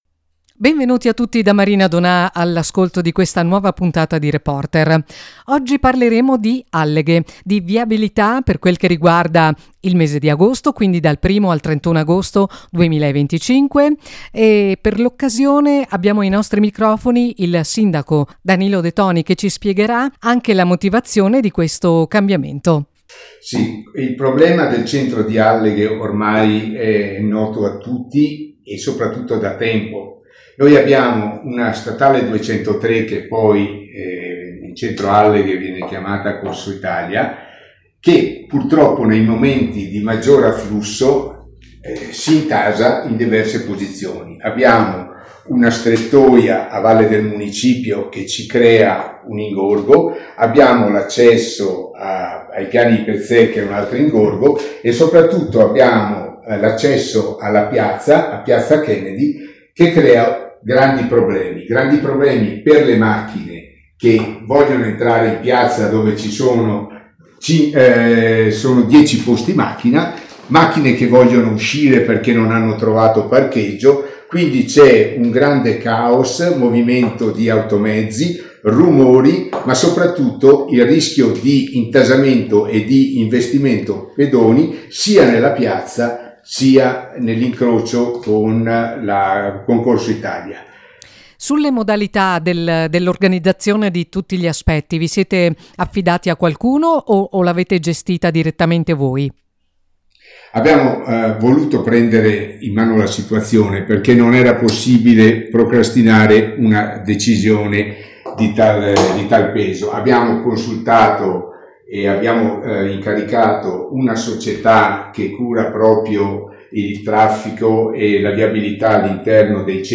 ZTL AD ALLEGHE, PARLA IL SINDACO DANILO DE TONI
OSPITE: Danilo De Toni, sindaco di Alleghe